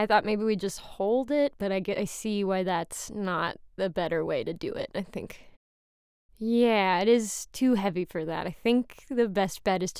expresso/ex04-ex02_confused_001_channel1_499s.wav · kyutai/tts-voices at main